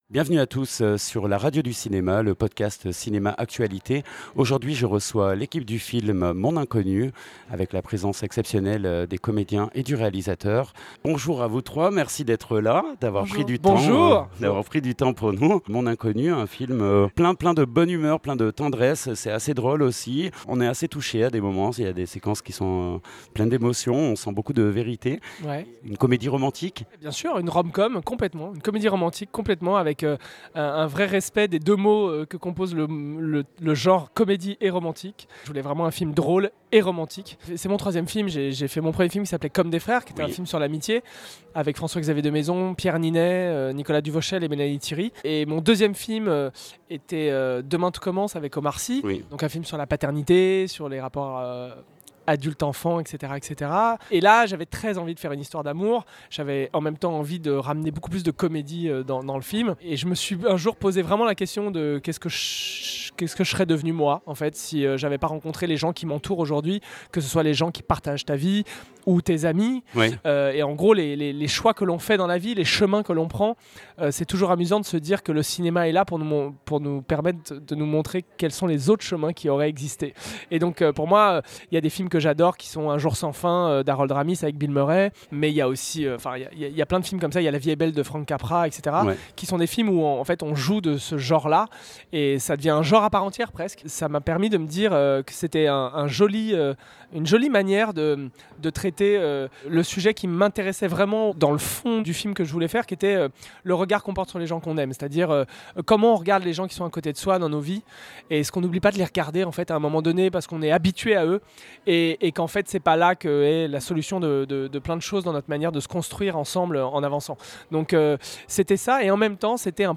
Mon inconnue - Interview